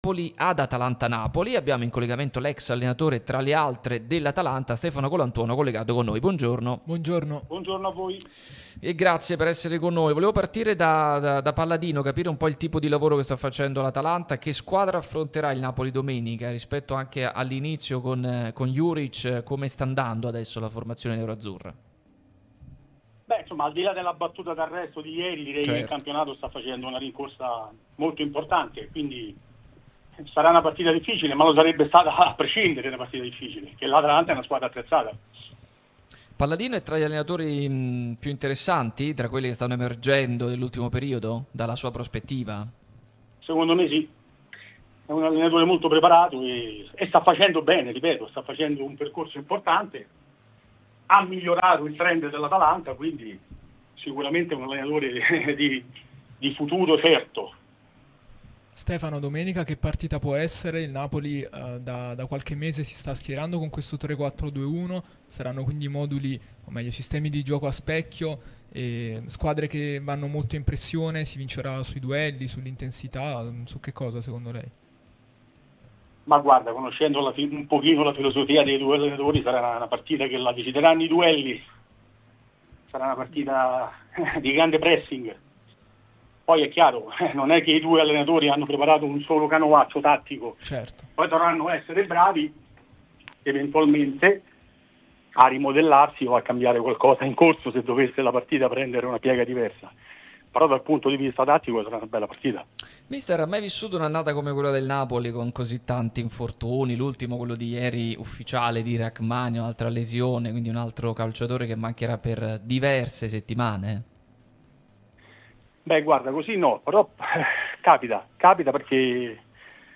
Stefano Colantuono, allenatore, è intervenuto su Radio Tutto Napoli, prima radio tematica sul Napoli, che puoi seguire sulle app gratuite (scarica qui per Iphone o per Android), qui sul sito anche in video.